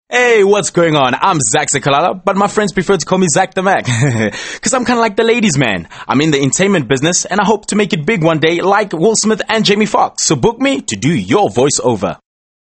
Vibrant voice artist,versitile,outspoken,south african voice,bright colourful voice,strong radio voice
Sprechprobe: eLearning (Muttersprache):
South African bright young male voice